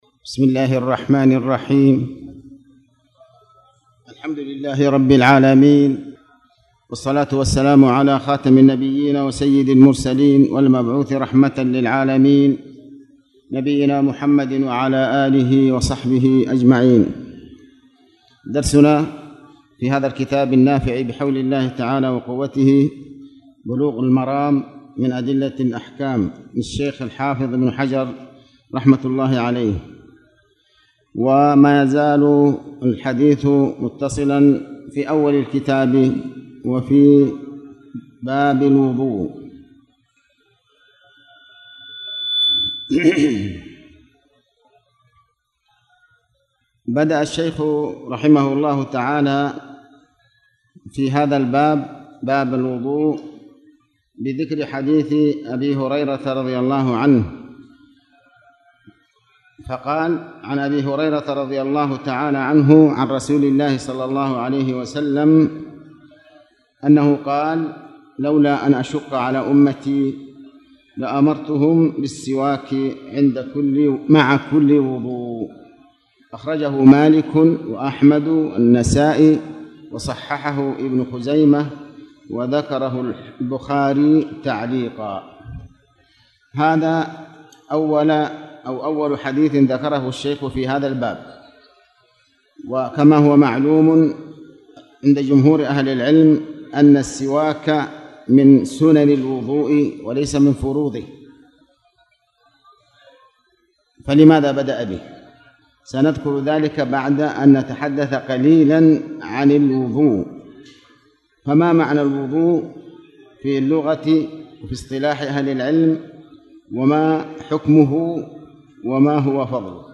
تاريخ النشر ٩ ربيع الأول ١٤٣٨ هـ المكان: المسجد الحرام الشيخ: علي بن عباس الحكمي علي بن عباس الحكمي باب الوضوء